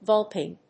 音節vul・pine 発音記号・読み方
/vˈʌlpɑɪn(米国英語)/